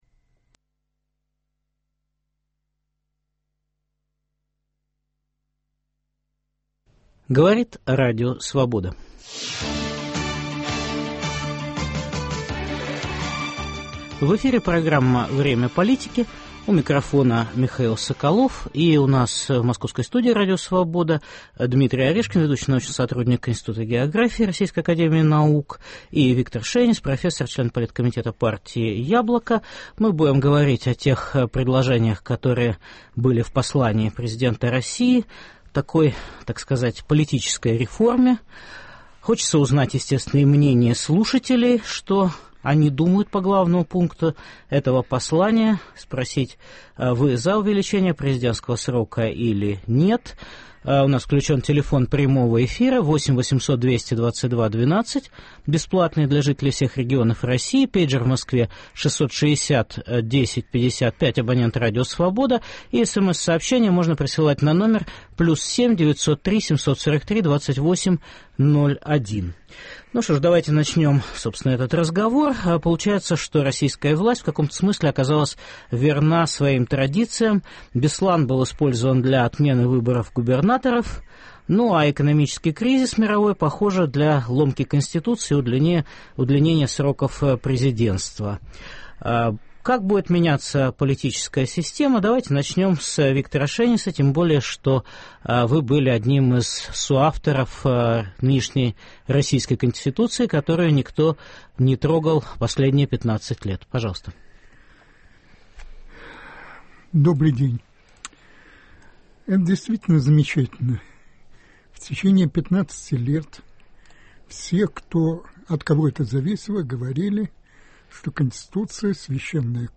В прямом эфире президентский план изменения Конституции России обсуждают профессор, член Политкомитета партии «Яблоко» Виктор Шейнис и политолог Дмитрий Орешкин